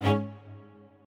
admin-leaf-alice-in-misanthrope/strings34_4_001.ogg at main